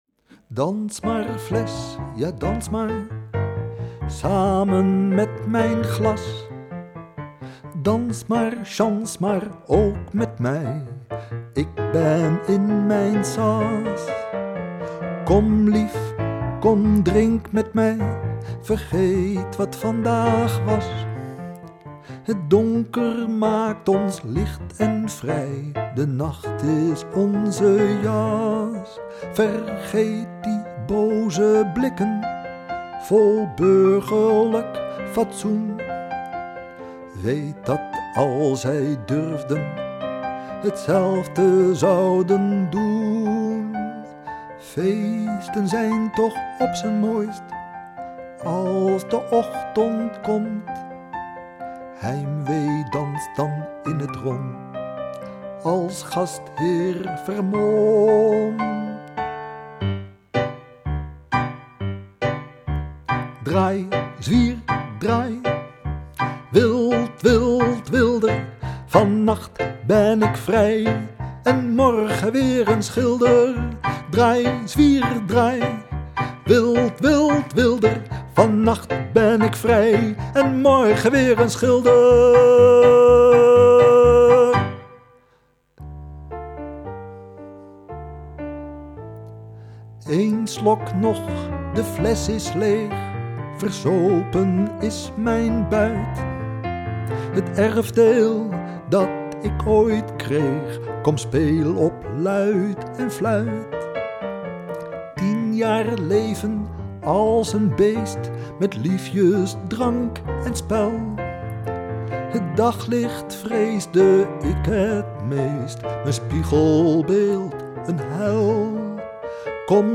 piano, zang